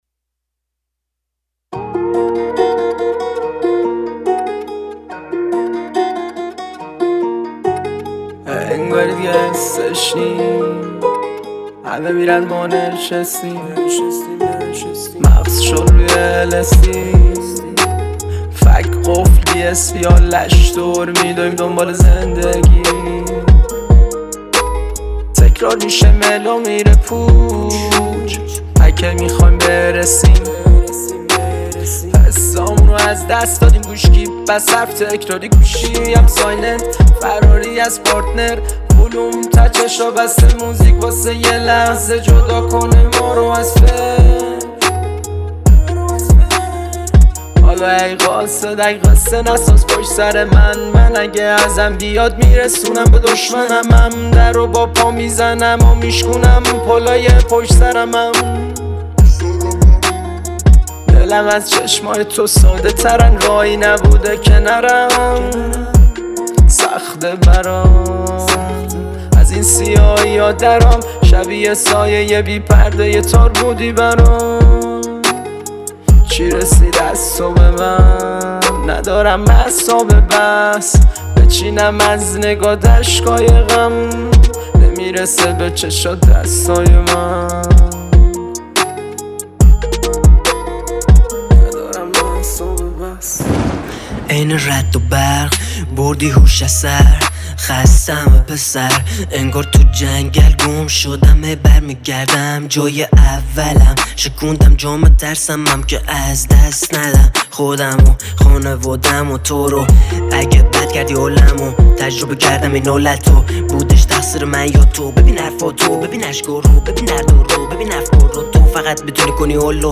موزیک رپ